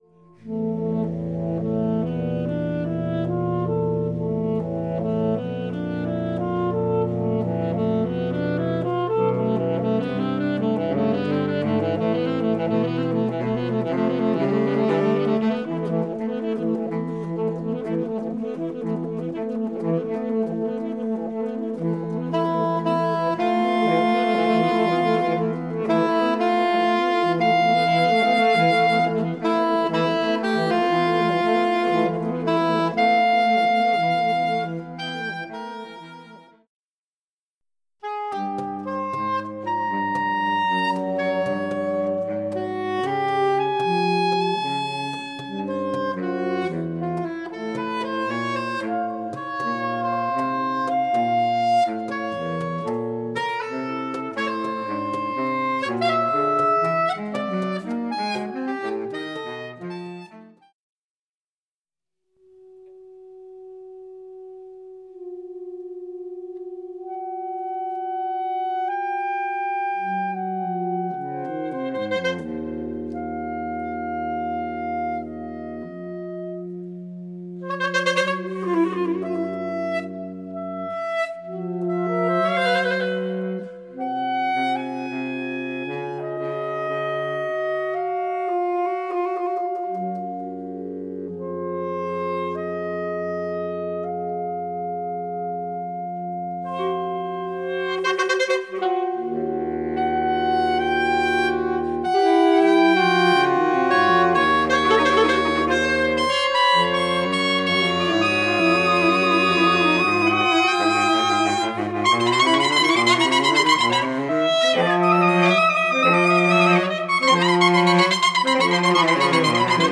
Ssax, ASax, TSax, BSax